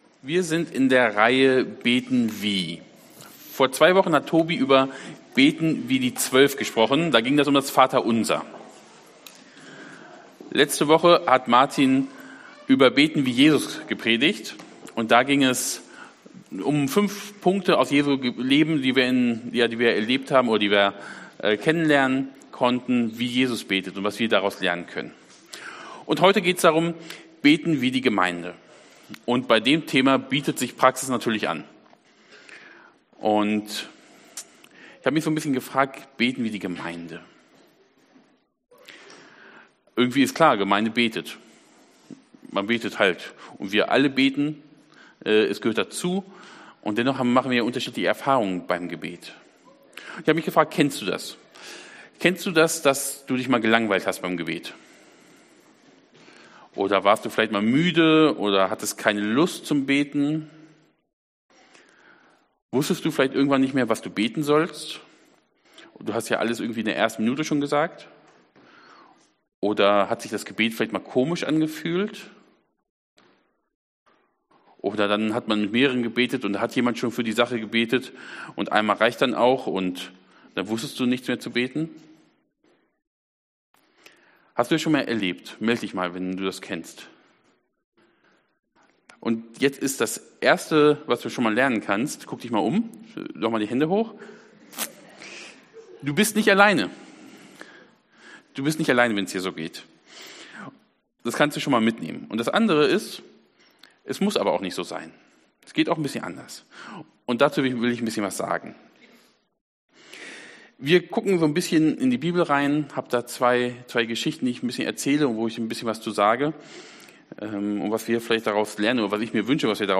Serie: BETEN WIE Dienstart: Predigt